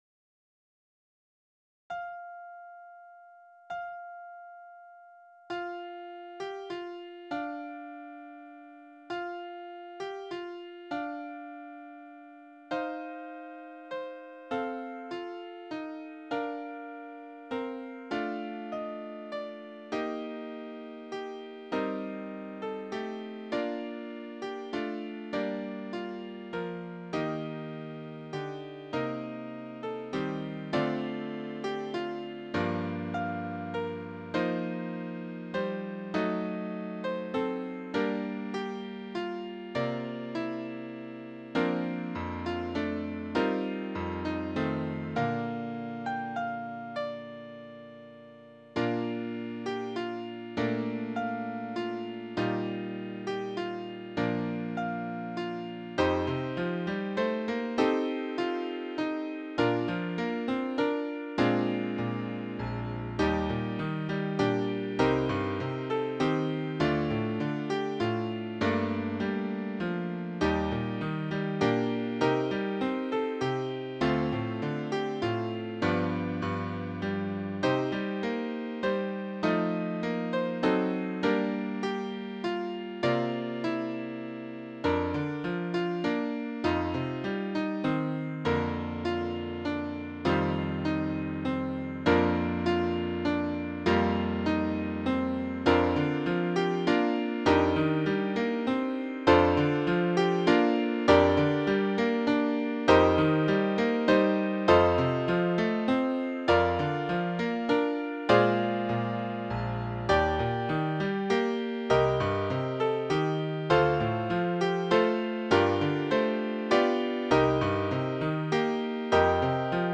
Piano Solo
Voicing/Instrumentation: Piano Solo We also have other 116 arrangements of " Silent Night ".